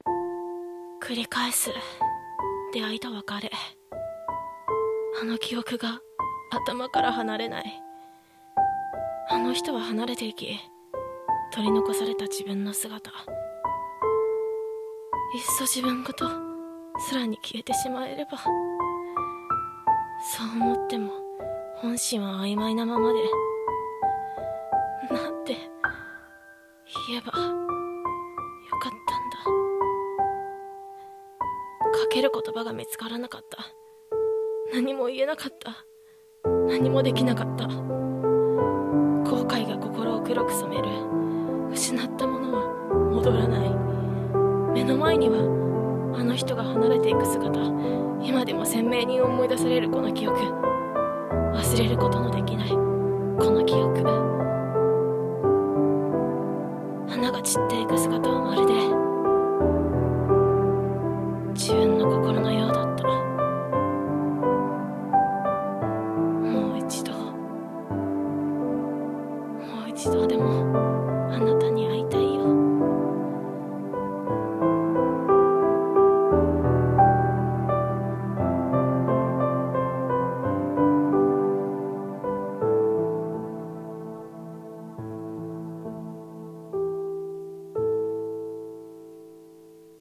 一人声劇台本｢散りゆく花と思い出される記憶｣